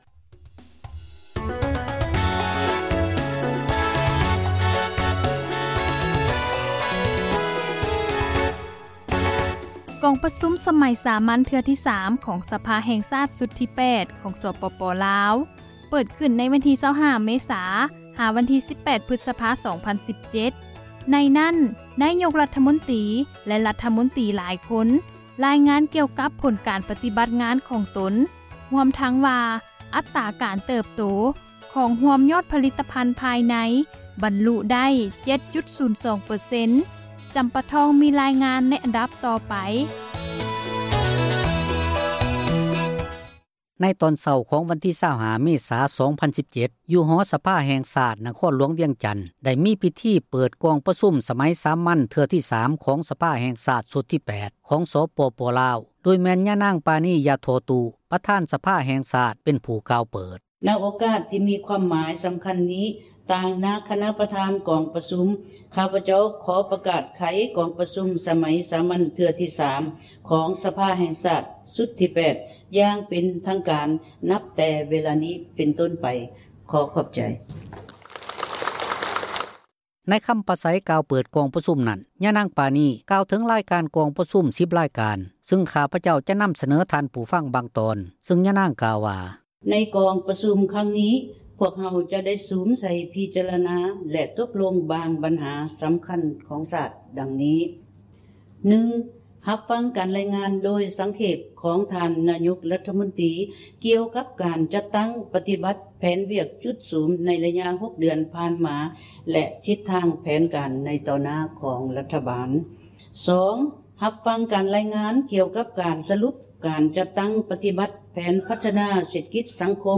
ໃນຕອນເຊົ້າຂອງວັນທີ 25 ເມສາ 2017 ຢູ່ຫໍສະພາແຫ່ງຊາດ ນະຄອນຫຼວງ ວຽງຈັນ ໄດ້ມີພີທີ ເປີດກອງປະຊຸມ ສະໄໝສາມັນ ເທື່ອທີ 3 ຂອງສະພາແຫ່ງຊາດ ຊຸດທີ VIII ຂອງ ສປປລາວ ໂດຍແມ່ນ ຍານາງ ປານີ ຢາທໍ່ຕູ້ ປະທານ ສະພາແຫ່ງຊາດ ເປັນຜູ້ກ່າວເປີດ ດັ່ງມີ ເນື້ອໃນວ່າ: ສຽງ...
ຫລັງຈາກນັ້ນ ທ່ານ ທອງລຸນ ສີສຸລິດ ນາຍົກຣັຖມົນຕຣີ ສປປລາວ ກໍໄດ້ຣາຍງານ ໂດຍສັງເຂບ ຕໍ່ກອງປະຊຸມ ສະພາແຫ່ງຊາດ ກ່ຽວກັບ ການປະຕິບັດງານ ໃນລະຍະ 6 ເດືອນ ຜ່ານມາ ແລະສເນີແຜນການ ວຽກງານ 6 ເດືອນ ຕໍ່ໄປຂອງ ປີ 2017 ຊຶ່ງຂ້າພະເຈົ້າ ຈະນໍາເອົາ ຄໍາເຫັນ ບາງຕອນຂອງທ່ານ ຮວມທັງຂໍ້ຄົງຄ້າງ ຈໍານວນນຶ່ງ ທີ່ທ່ານ ຈະແກ້ໄຂ ມາສເນີທ່ານ: ສຽງ..